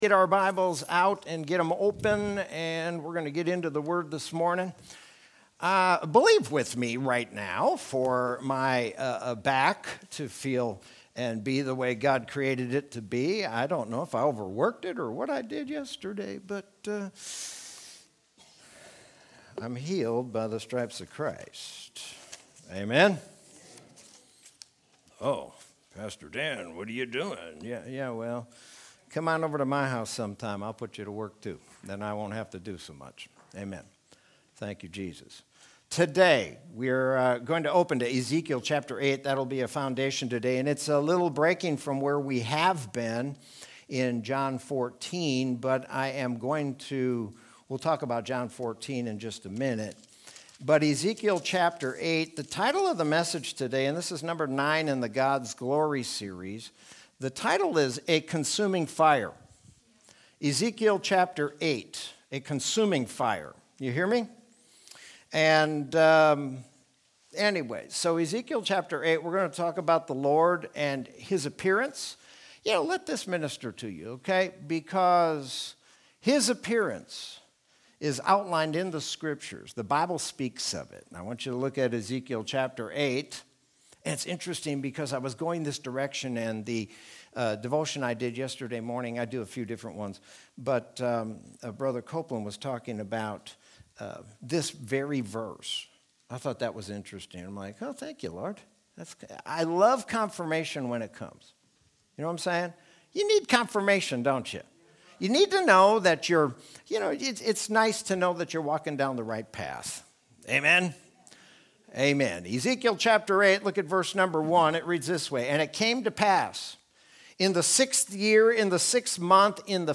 Sermon from Sunday, April 25th, 2021.